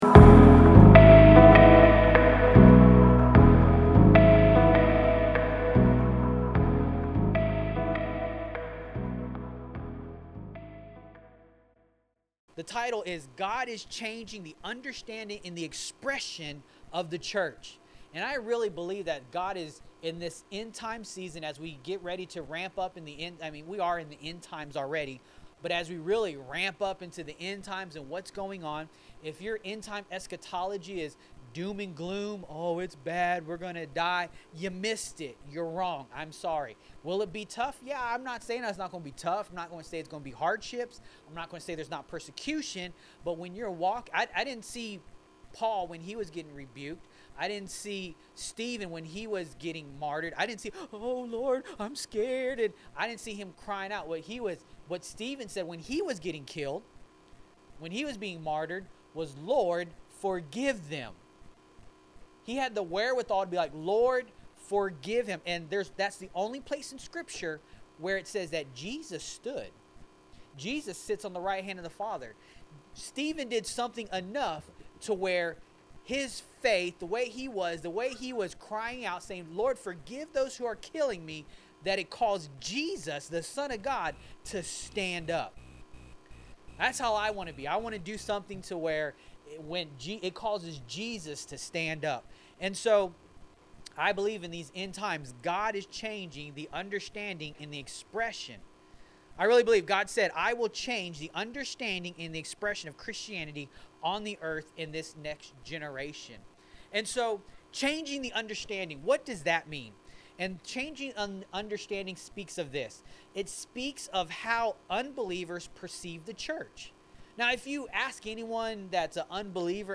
Sermons | Forerunner Church